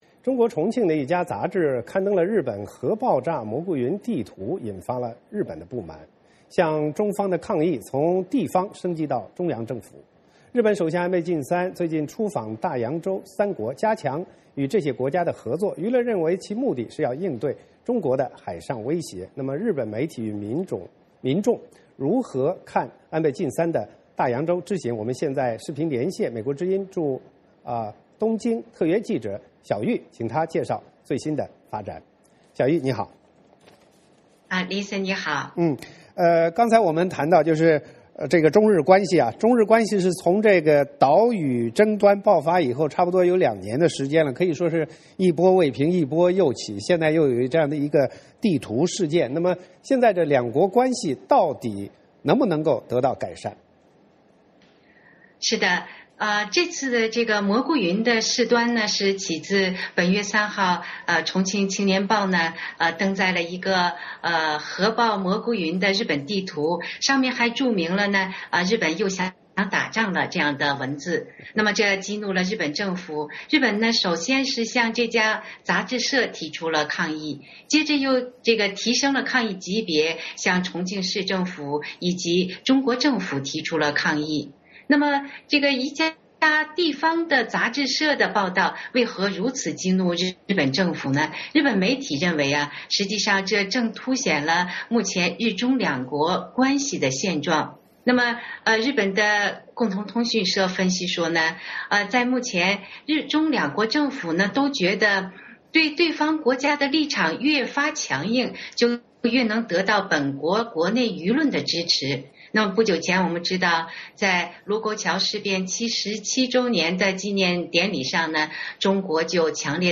VOA连线：重庆杂志“蘑菇云”地图引日本不满